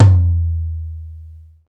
TOM XTOMLO0C.wav